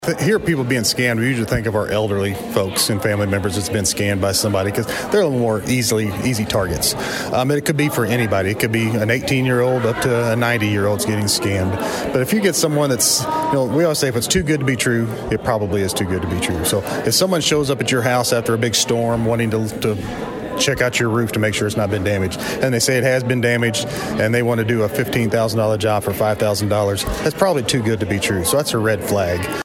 Missouri State Highway Patrol Shares Fraud Awareness Tips at Farmington Chamber Luncheon
During Thursday’s Farmington Regional Chamber of Commerce Luncheon